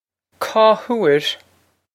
Pronunciation for how to say
caw hoor?
This is an approximate phonetic pronunciation of the phrase.